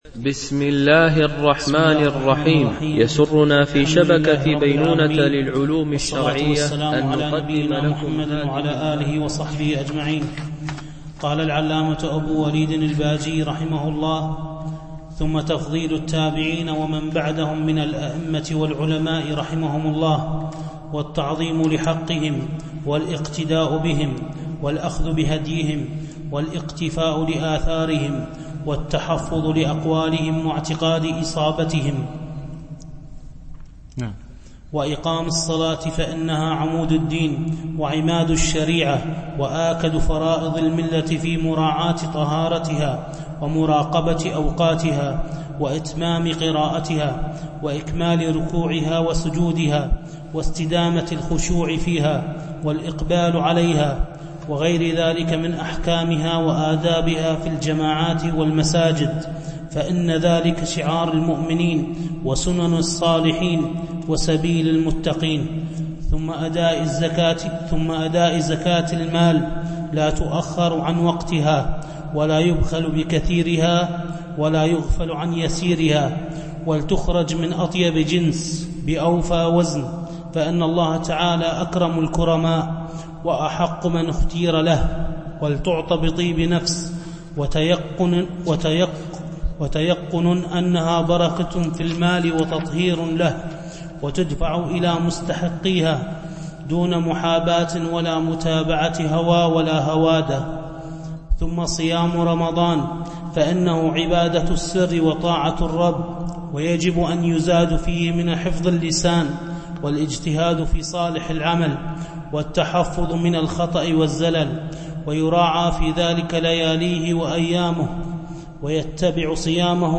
شرح النصيحة الولدية ـ الدرس 5